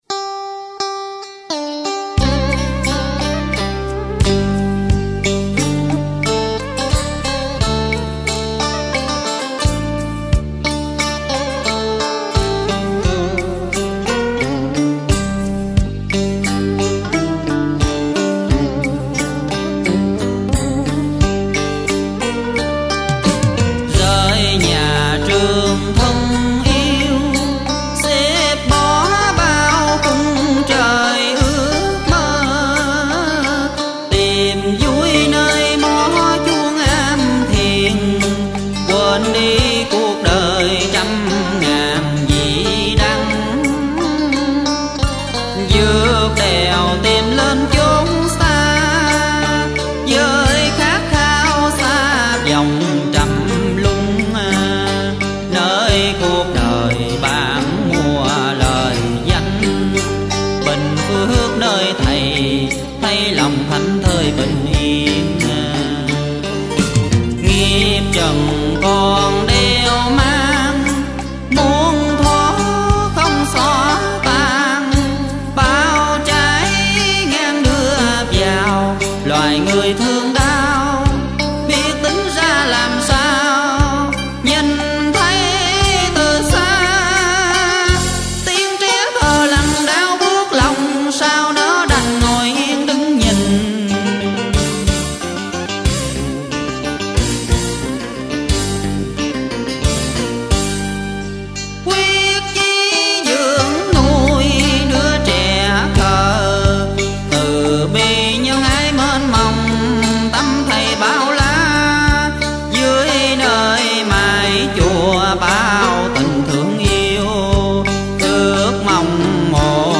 Category: Cổ Nhạc